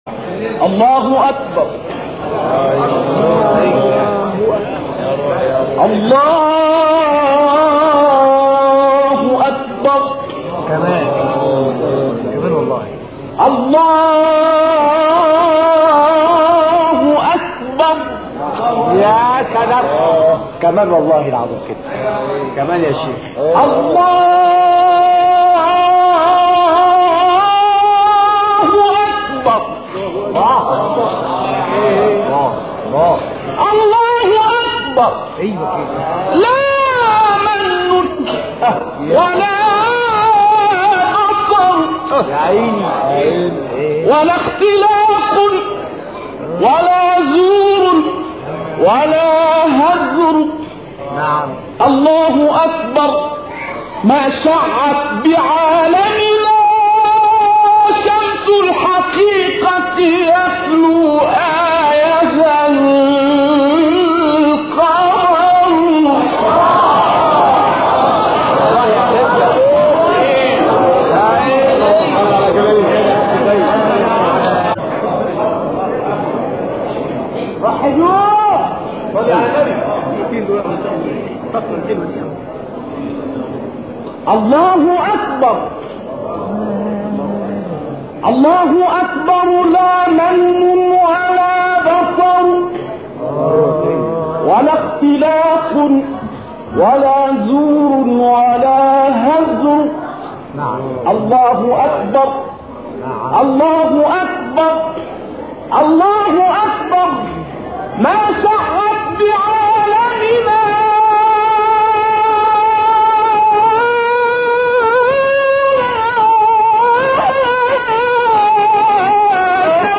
صوت | ابتهالی شنیدنی با نوای ملکوتی نقشبندی
به مناسبت سالروز درگذشت سیدمحمد نقشبندی، قاری و مبتهل به نام مصری ابتهالی شنیدنی از این مبتهل مصری را می‌شنوید.
برچسب ها: ابتهال ، نقشبندی ، ایران ، مصر ، قاریان ، ایکنا